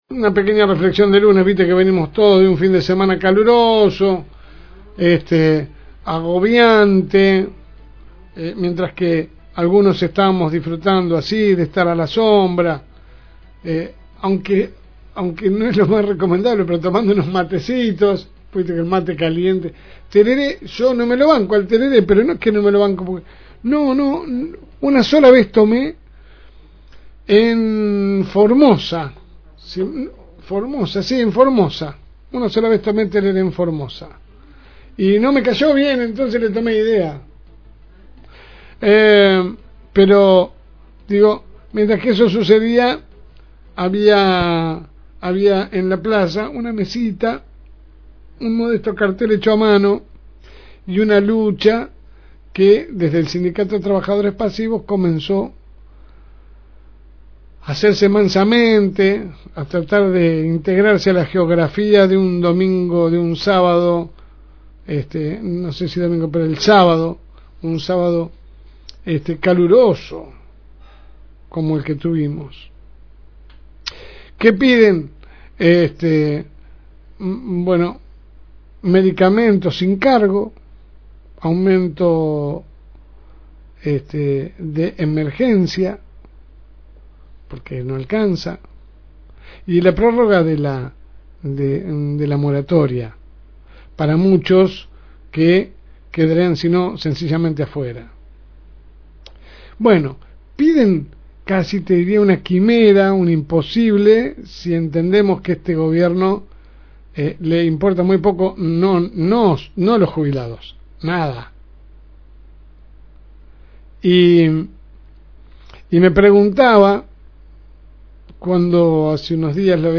AUDIO – Editorial de LSM – FM Reencuentro